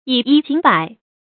注音：ㄧˇ ㄧ ㄐㄧㄥˇ ㄅㄞˇ
以一警百的讀法